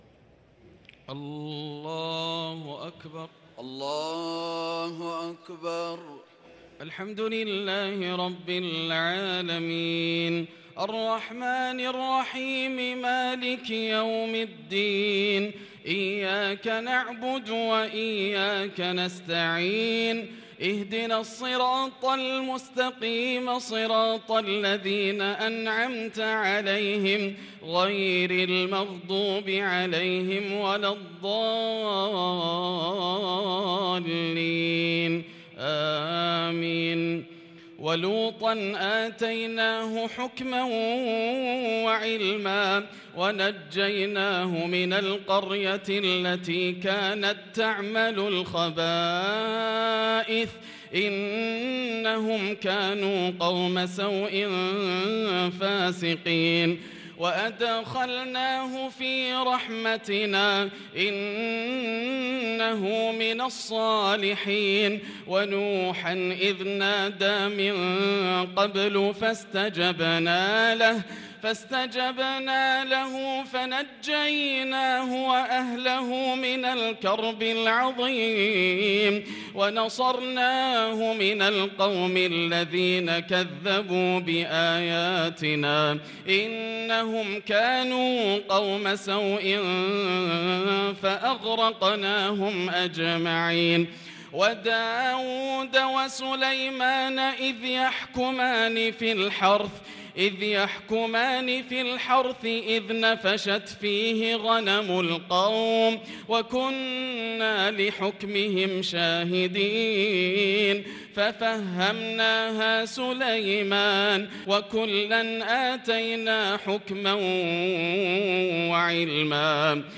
صلاة التراويح ليلة 30 رمضان 1443 للقارئ ياسر الدوسري - التسليمتان الأخيرتان صلاة التراويح